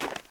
PixelPerfectionCE/assets/minecraft/sounds/step/snow3.ogg at dd1e482ed4fd1e4cf7454b1e91ec283c3081c460
snow3.ogg